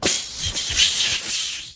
BRUITAGES
1 channel